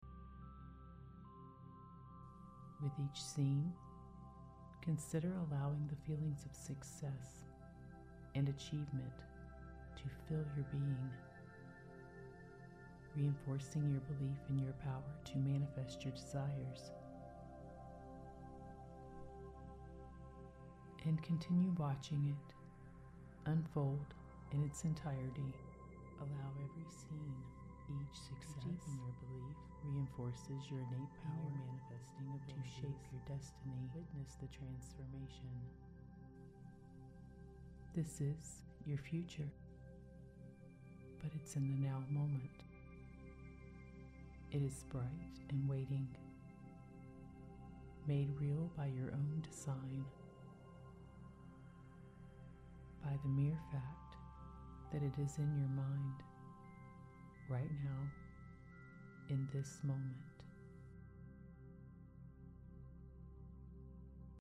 Dual Induction Technology: Engages multiple brain areas simultaneously, enhancing the absorption of positive suggestions.
Theta Binaural Track: Integrates theta music and binaural tones to facilitate deep relaxation and ease of entering trance states, essential for profound transformation.
The binaural beats require headphones to be effective and entrain the brain properly. 2.) The audio intentionally dips to a lower volume in the center of this track to engage and impress the subconscious mind in deeper way.